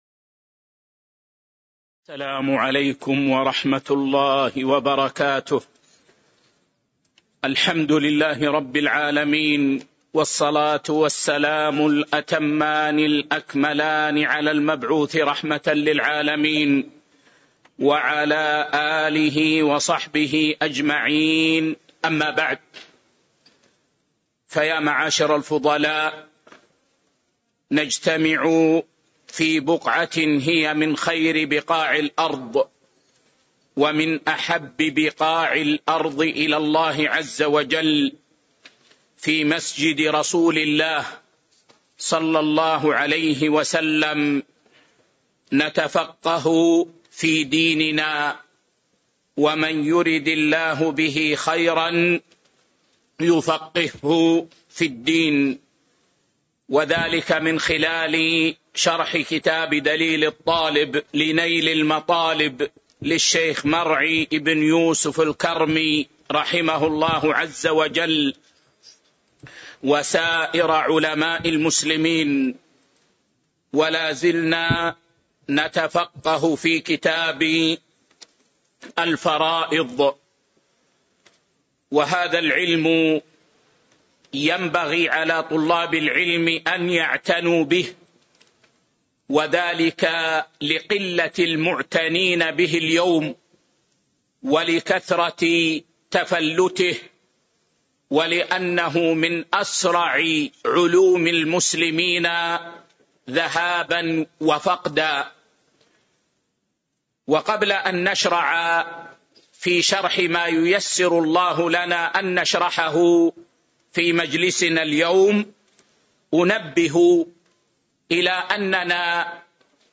تاريخ النشر ٦ ربيع الأول ١٤٤٥ هـ المكان: المسجد النبوي الشيخ